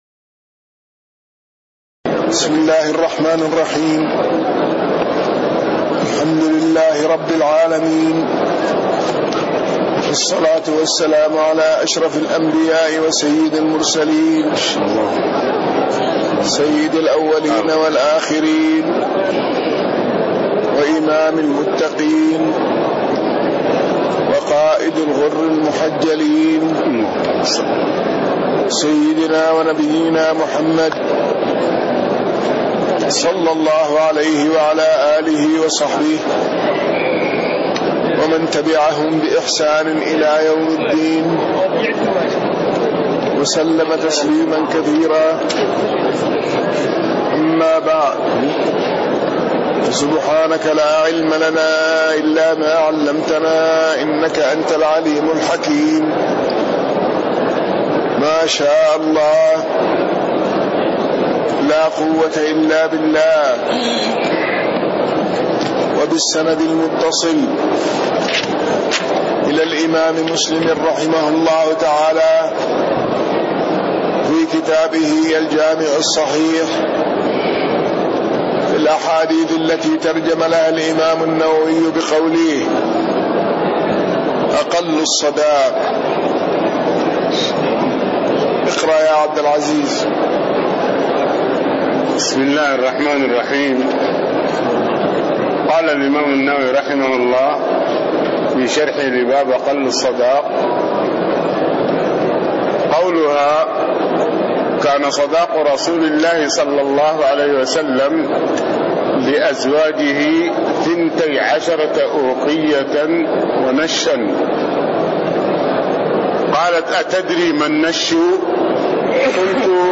تاريخ النشر ١٢ جمادى الآخرة ١٤٣٤ هـ المكان: المسجد النبوي الشيخ